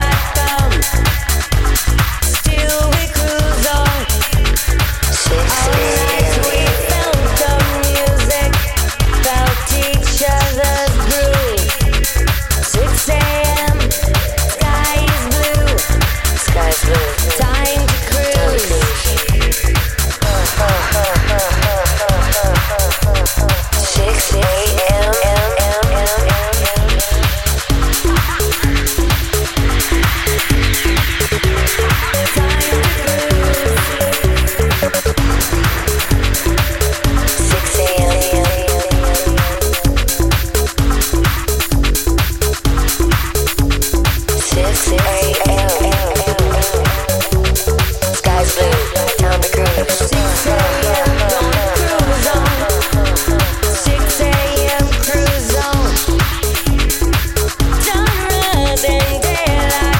Электронная
Шестой выпуск сборника клубной мgузыки Екатеринбурга.